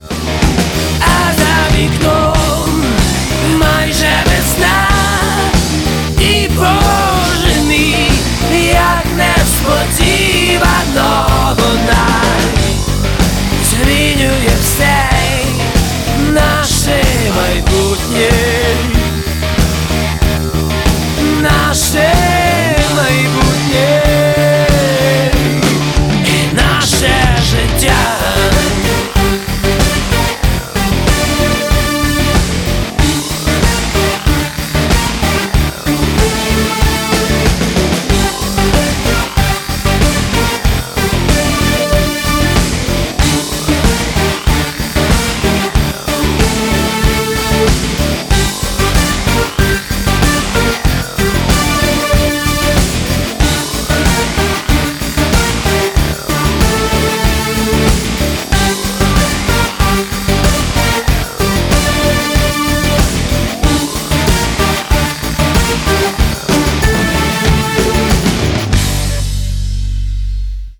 громкие
украинский рок